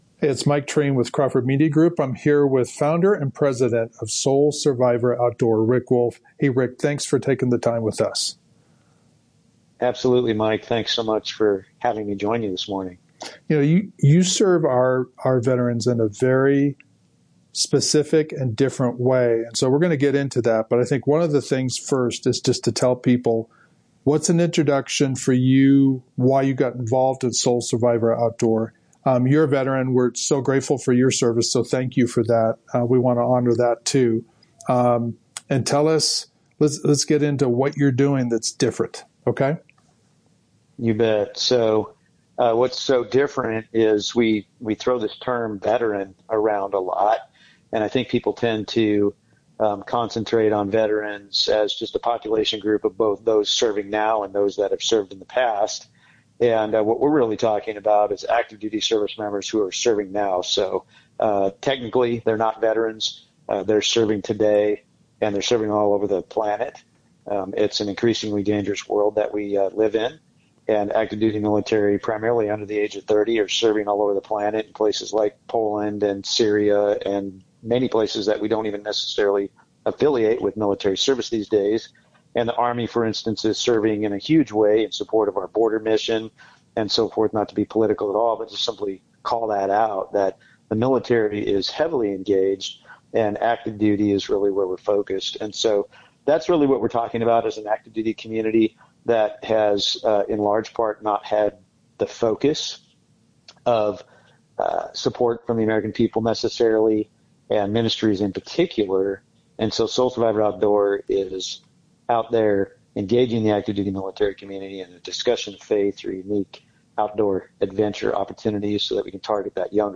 Talking Truth Radio Program